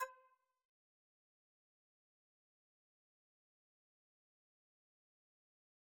cursor_style_4.wav